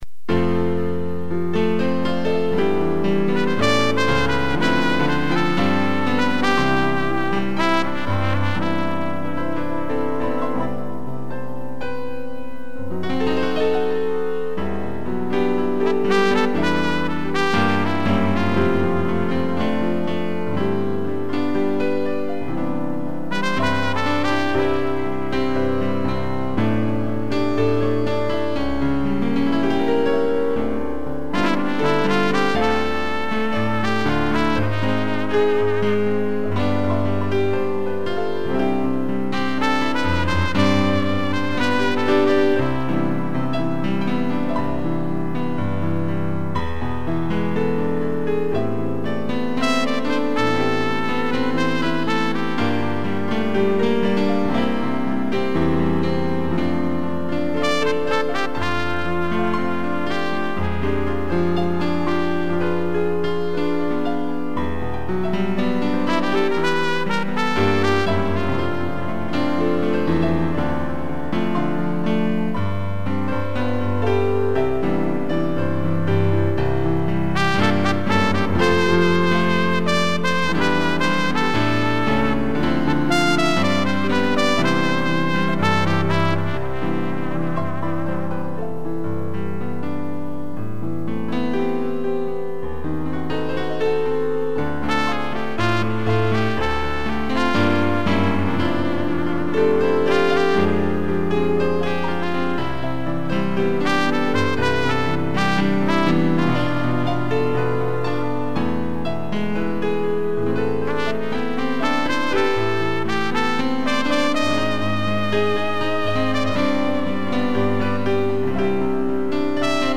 2 pianos e trompete
(instrumental)